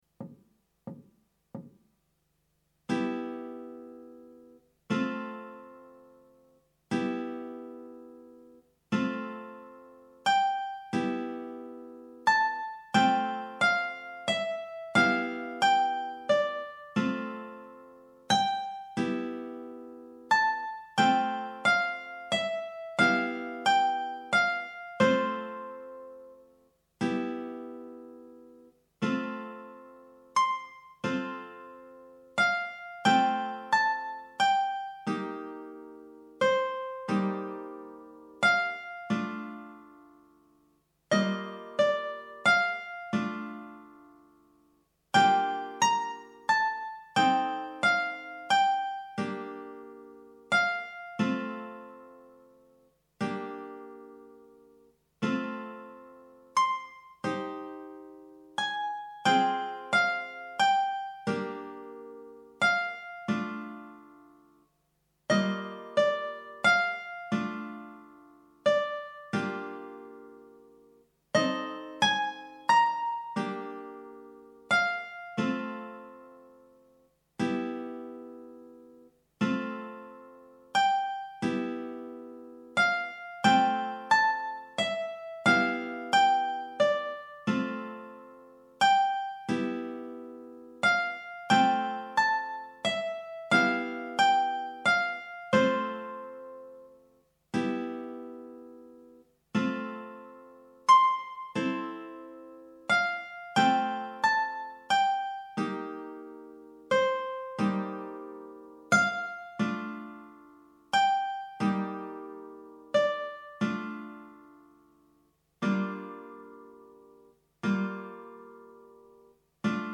minus Guitar 2